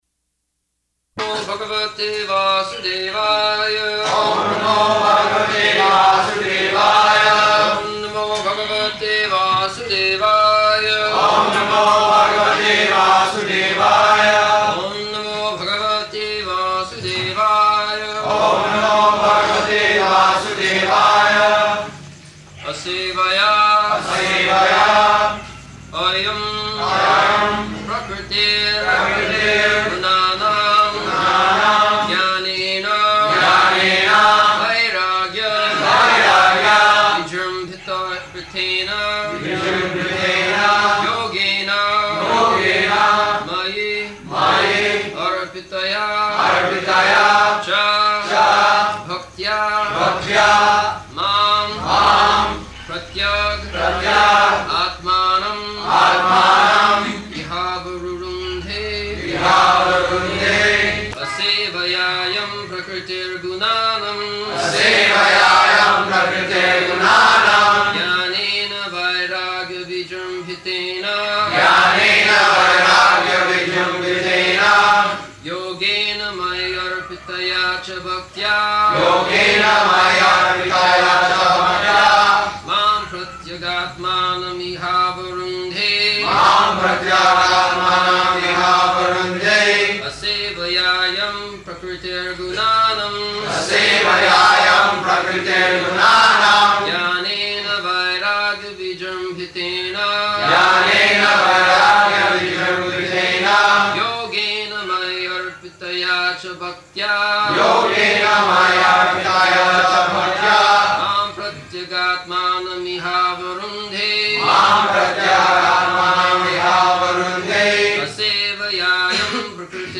November 27th 1974 Location: Bombay Audio file
[devotees repeat]
[dog barking in background] Just like the dog is barking.